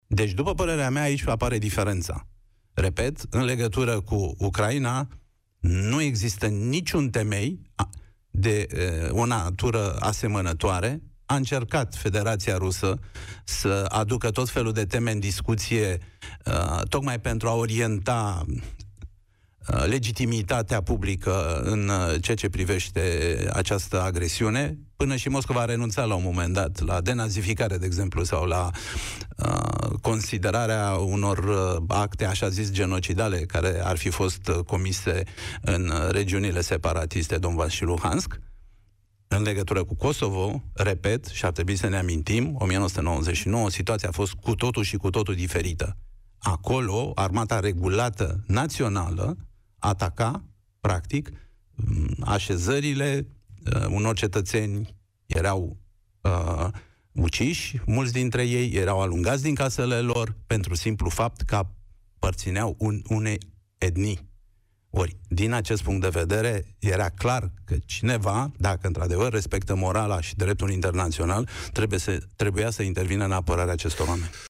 Cristian Diaconescu, fost ministru de externe al României, este invitatul lui Cătălin Striblea în emisiunea „România în Direct”.